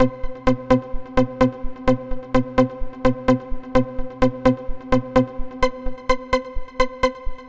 前卫的房子，低音合成器的领导
描述：在Progressive House、Electro House和更多的地方，这是一个伟大的失谐合成器线索。
Tag: 128 bpm Dance Loops Synth Loops 1.26 MB wav Key : G